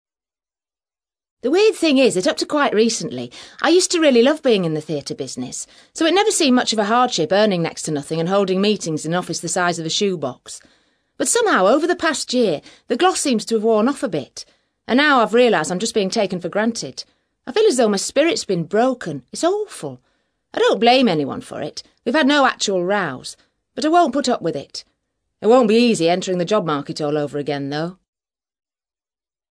ACTIVITY 162: You will hear five short extracts in which five people are talking about problems related to their work.